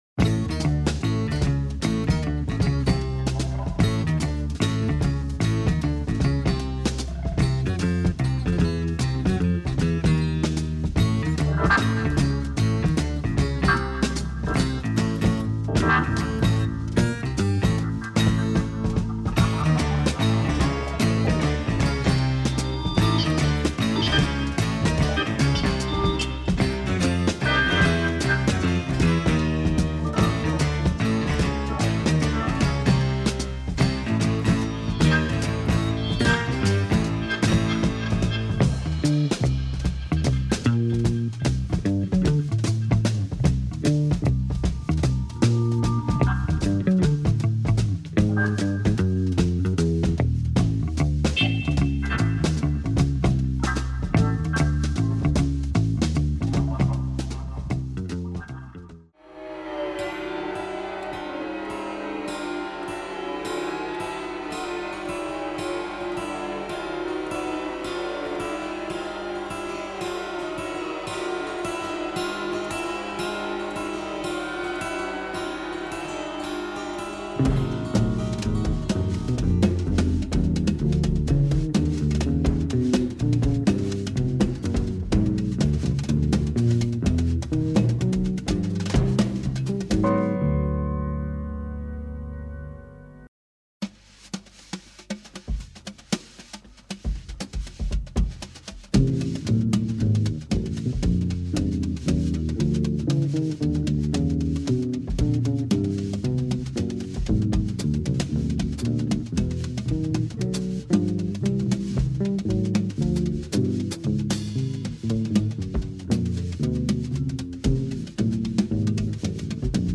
Dark psychedelic sounds and jazz.